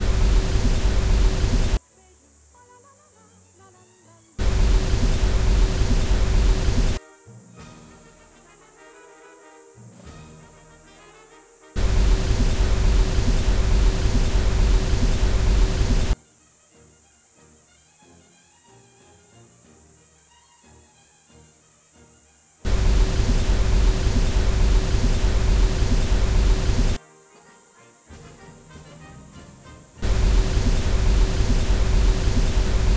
1. Ett musikavsnitt i format 44,1 kHz 24 bitar har nivåsänkts med 90 dB.
3. Sedan har resultatet av steg 2 nivåhöjts med 60 dB.
Därför har jag fått ta en kort snutt tystnad och loopat den, därför har tystnaden en onaturlig och pulserande karaktär.
Tystnaden består främst av en blandning av ventilationsljud och mikrofonbrus.